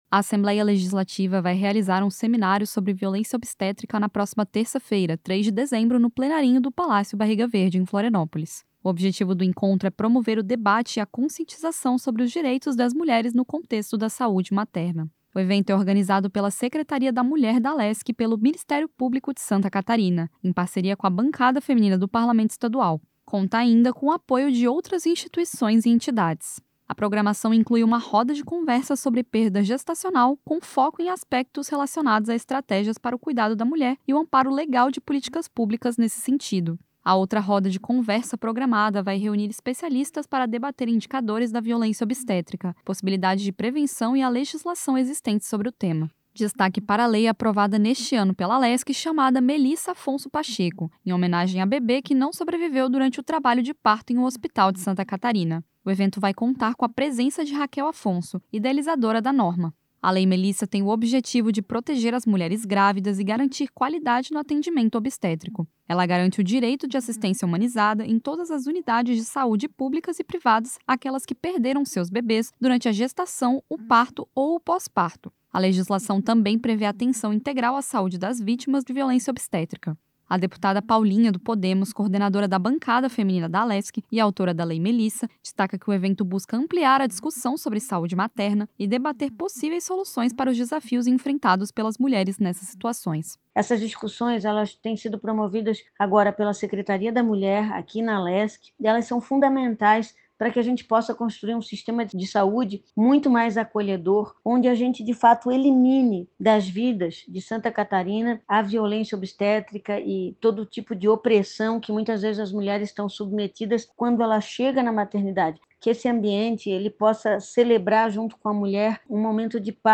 Entrevista com:
- deputada Paulinha (Podemos), coordenadora da Bancada Feminina da Alesc.